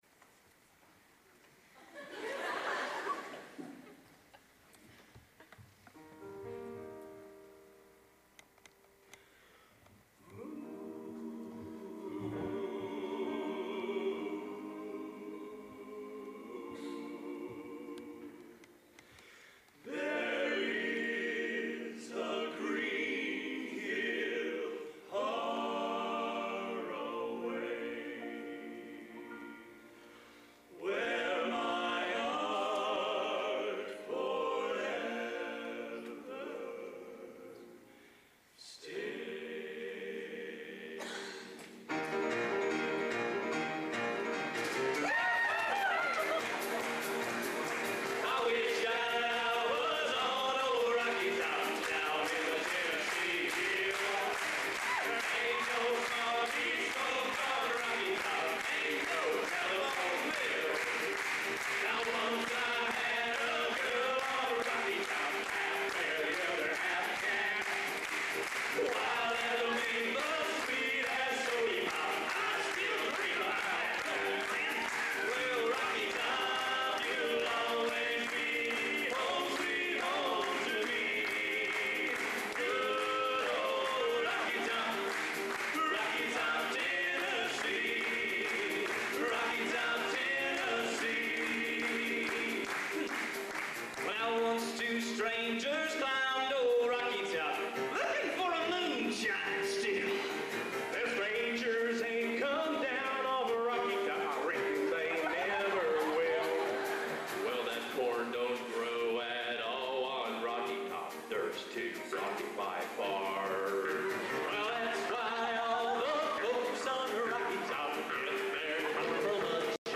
Location: Purdue Memorial Union, West Lafayette, Indiana